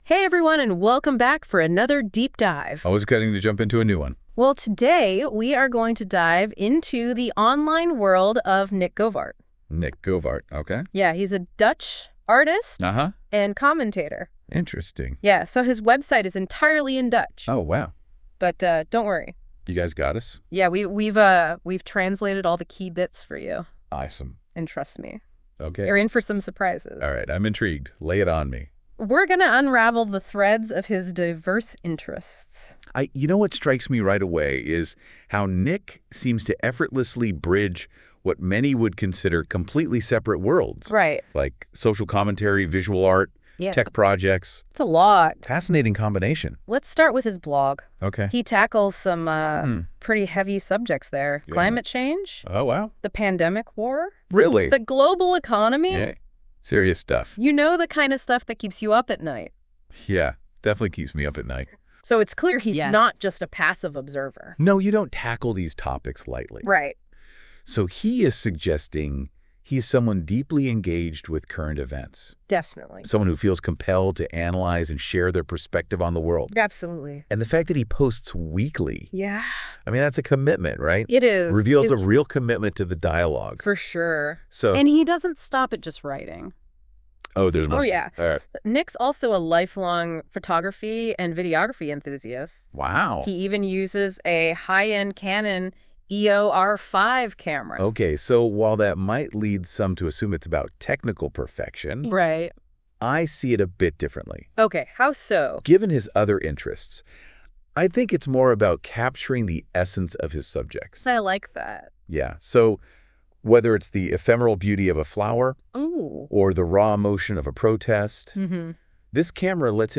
Ik heb mijn website laten analyseren en daar is een heuse podcast van gemaakt door deze AI.